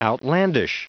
Prononciation du mot outlandish en anglais (fichier audio)
Prononciation du mot : outlandish